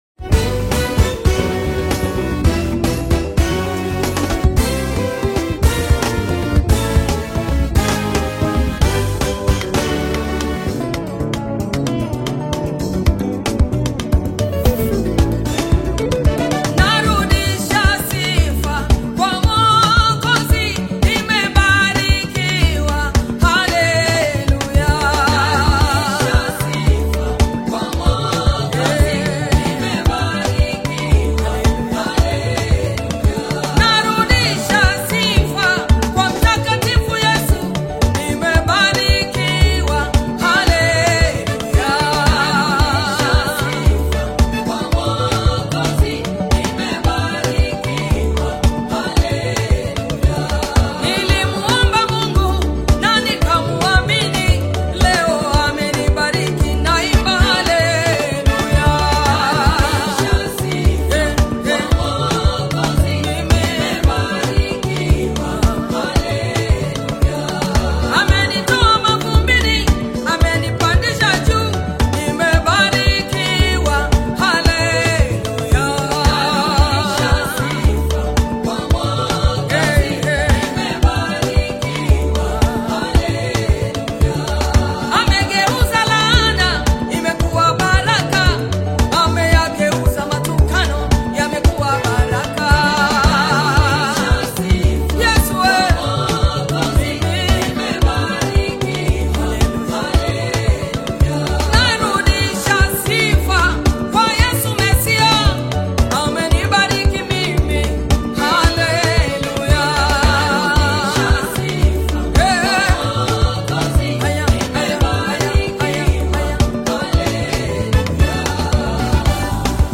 • Mix & Mastering: The production is exceptionally clean.